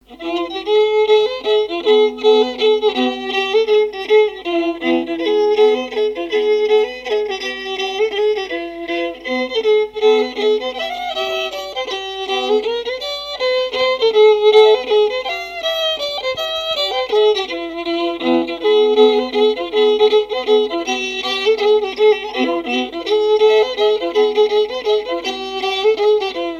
danse : mazurka
répertoire musical au violon
Pièce musicale inédite